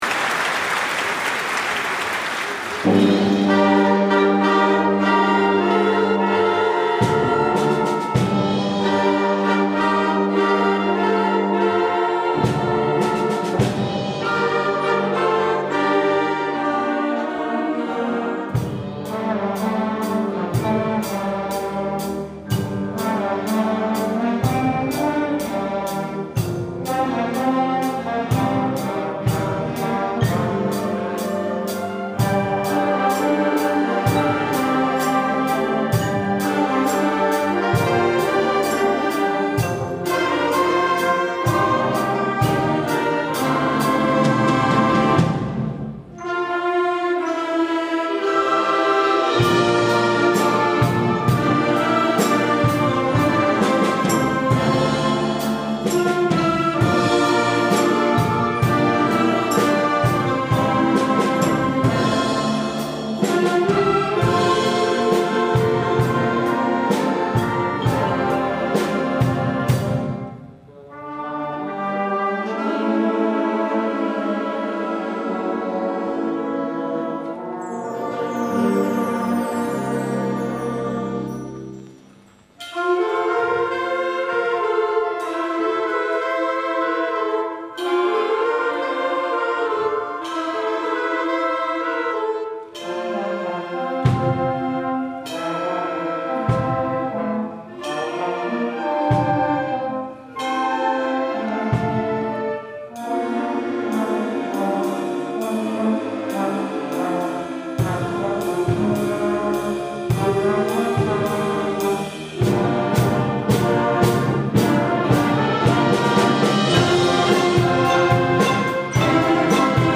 Junior Wind Band